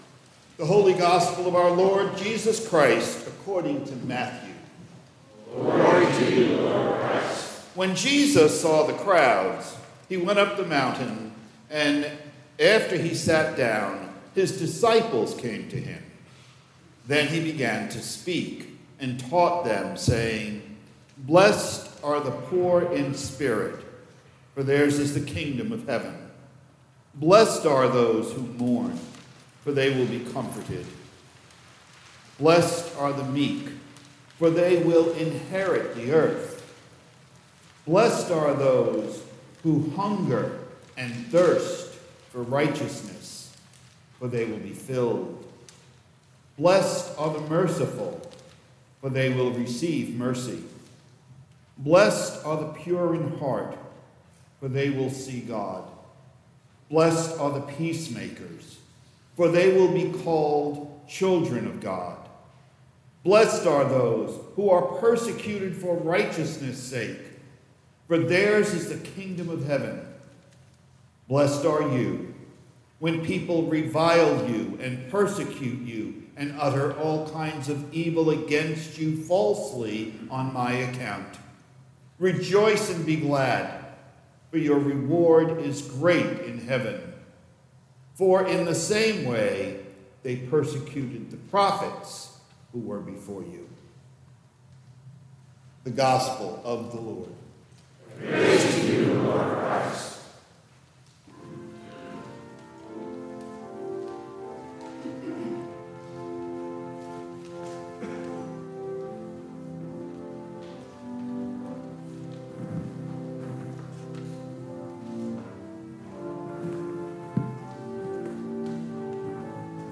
Latest Sermons & Livestreams